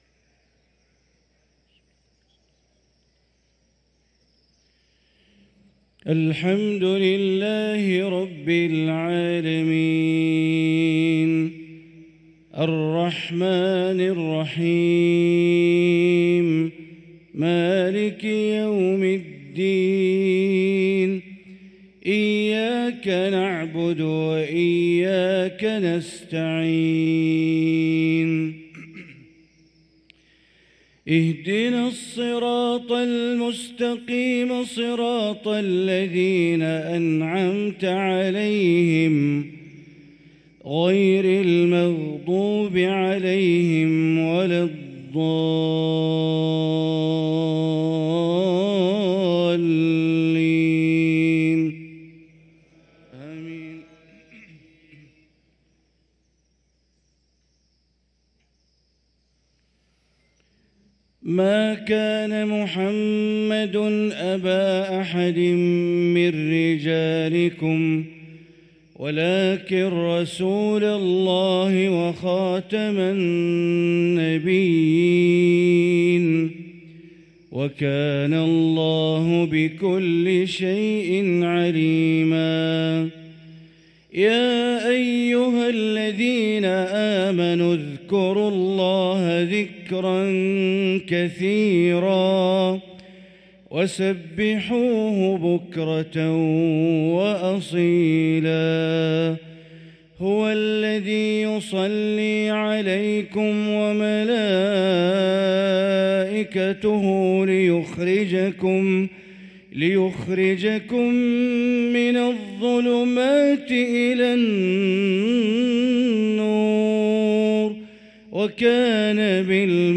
صلاة المغرب للقارئ بندر بليلة 13 رجب 1445 هـ
تِلَاوَات الْحَرَمَيْن .